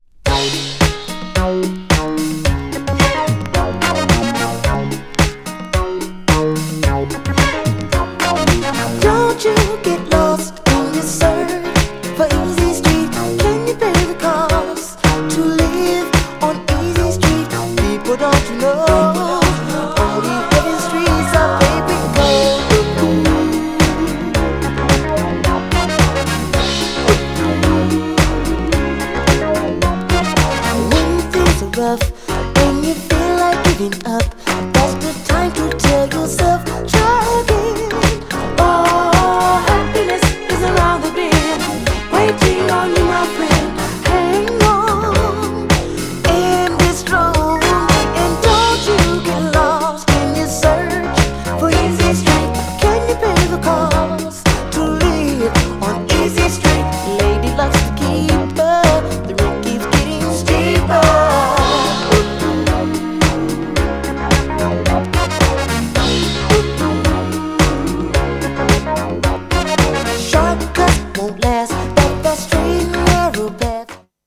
ウェッサイファンにも人気のベースブリブリのFUNK!!
GENRE Dance Classic
BPM 111〜115BPM
# GROOVY
# シンセ # ソウル # ハートウォーム